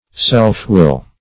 Self-will \Self`-will"\, n. [AS. selfwill.]